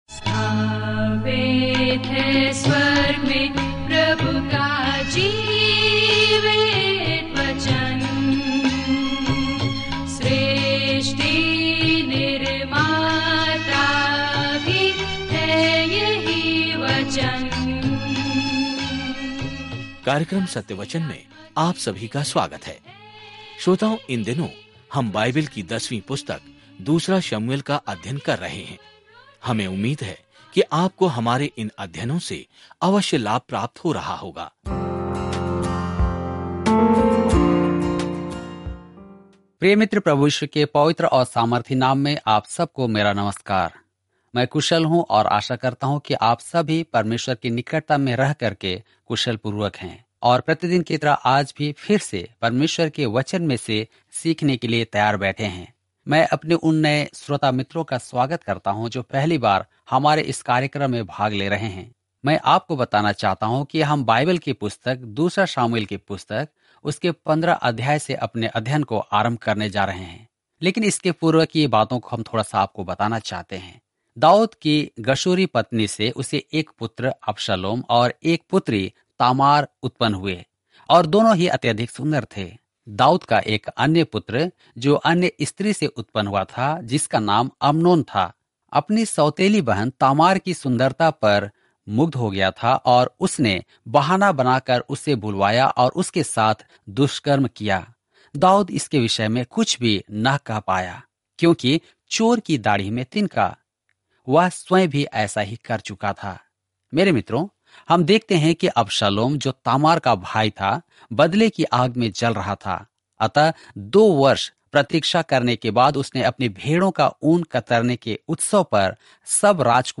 पवित्र शास्त्र 2 शमूएल 15 2 शमूएल 16 दिन 8 यह योजना प्रारंभ कीजिए दिन 10 इस योजना के बारें में ईश्वर के साथ इज़राइल के रिश्ते की कहानी भविष्यवक्ताओं की सूची में शामिल होने के साथ जारी है कि ईश्वर अपने लोगों से कैसे जुड़ता है। 2 सैमुअल के माध्यम से दैनिक यात्रा करें क्योंकि आप ऑडियो अध्ययन सुनते हैं और भगवान के वचन से चुनिंदा छंद पढ़ते हैं।